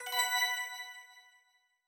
SFX_QuestComplete_Placeholder.wav